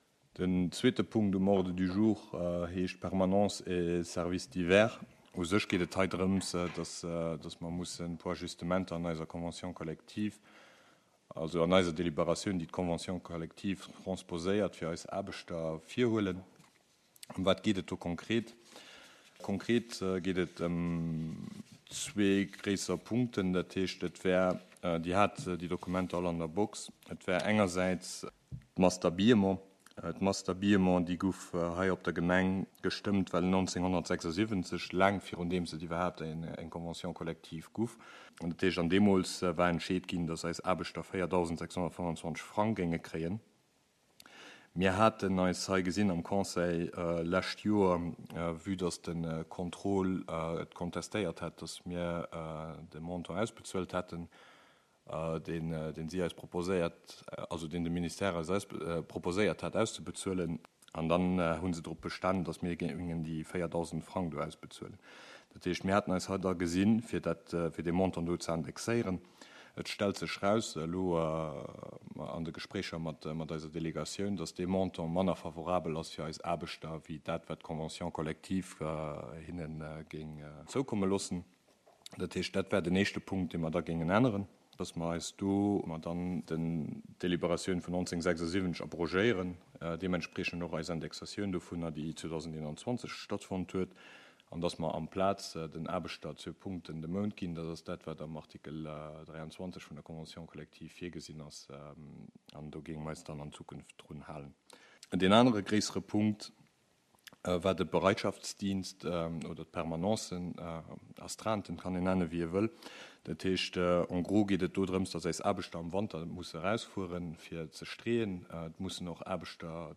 Conseil Communal  du mercredi 28 septembre 2022 à 19h00 heures Centre Culturel Larei en la salle Bessling